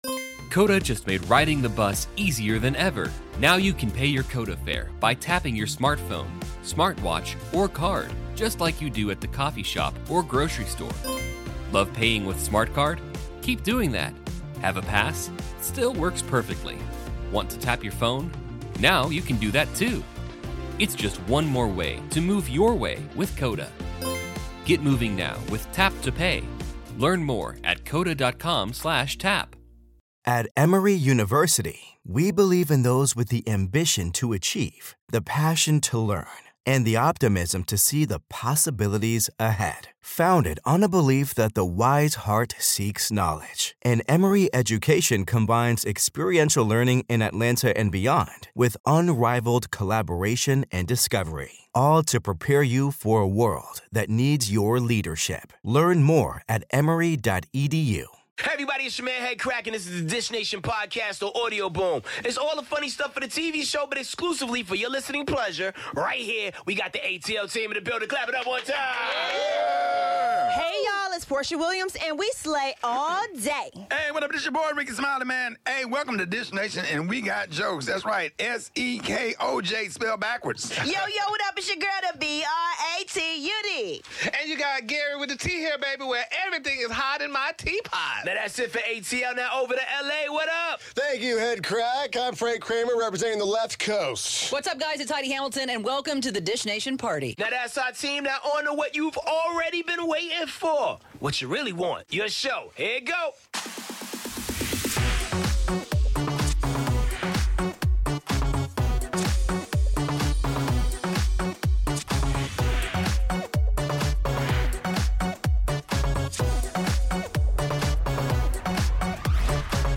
Guest host: Eva Marcille. The best moments from the MTV Movie and TV Awards and a Chris Pratt smash alert! Plus all the latest on Beyonce, Nas, Selena Gomez, Ariana Grande, Billy Dee Williams and much more!